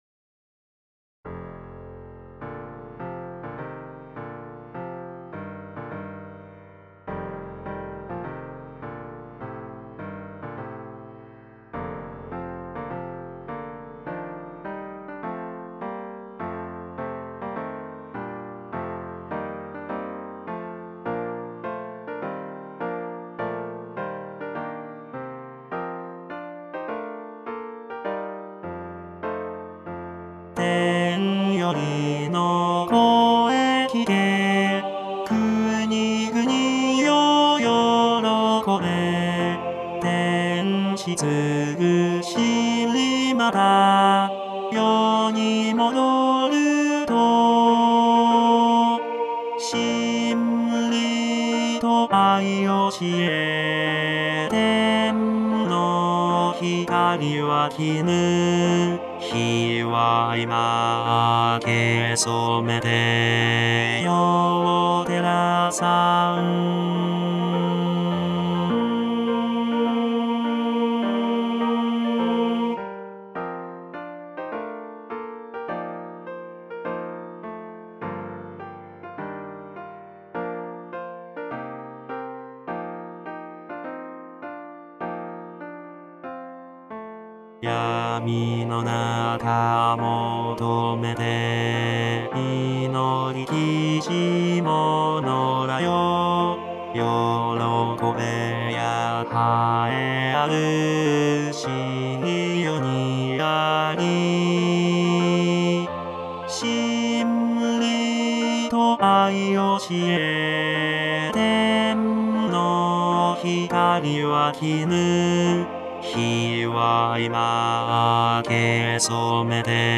バス（フレットレスバス音）